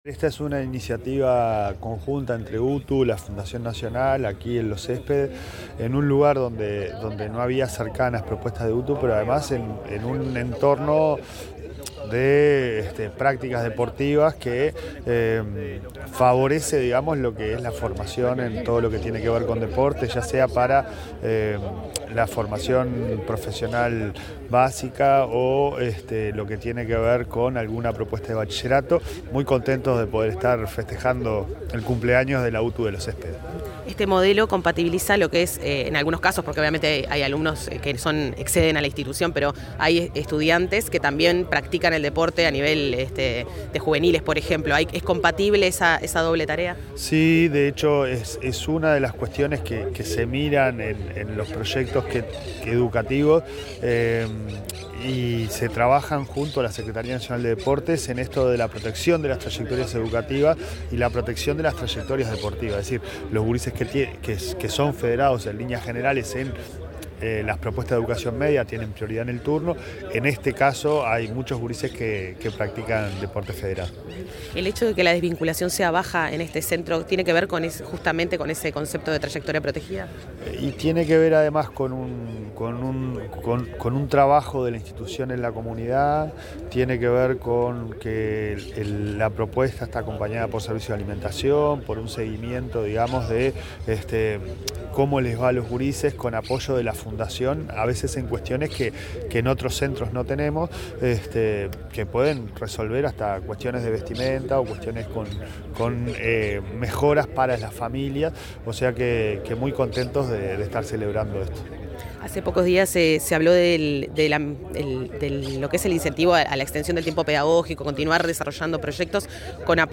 Declaraciones del presidente de ANEP, Pablo Caggiani
Declaraciones del presidente de ANEP, Pablo Caggiani 11/09/2025 Compartir Facebook X Copiar enlace WhatsApp LinkedIn En la celebración del 5.° aniversario de la UTU de la Ciudad Deportiva Los Céspedes del Club Nacional de Football, el titular de la Administración Nacional de Educación Pública, Pablo Caggiani, realizó declaraciones.